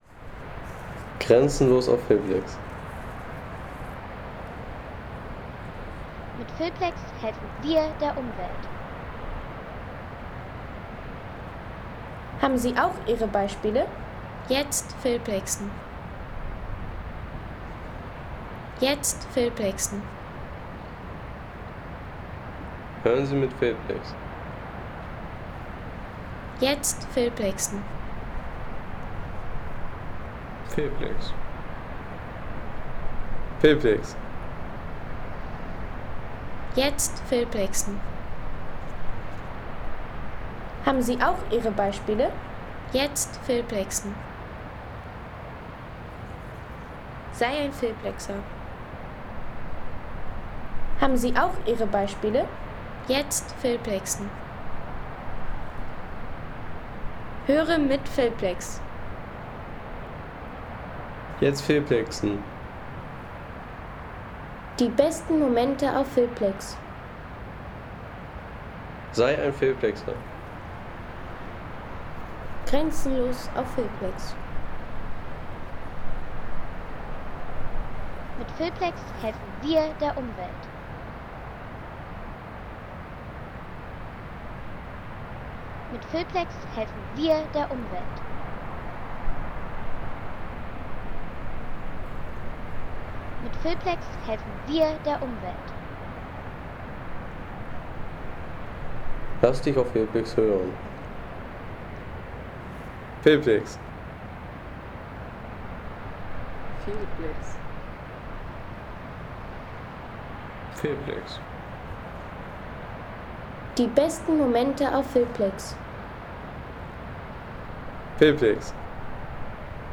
Frischer Wind und ewiger Schnee – Naturerlebnis am Kjelavatn.